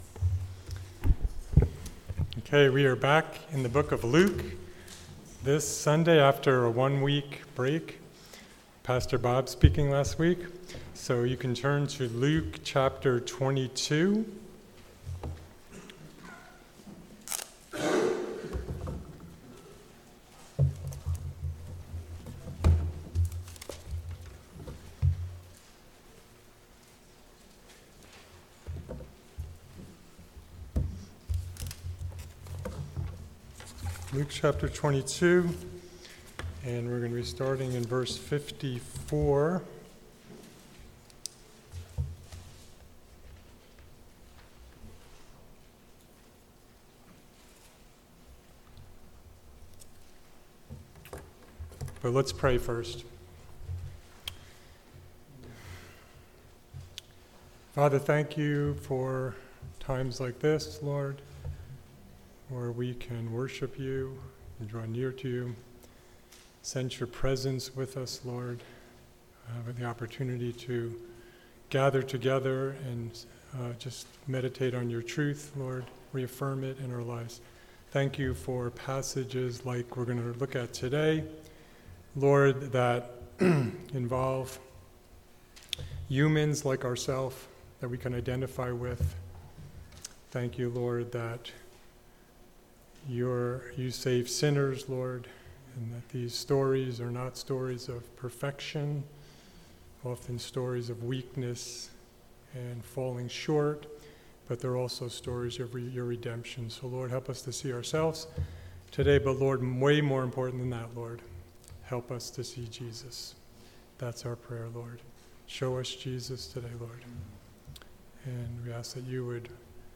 Sermons | The Bronx Household of Faith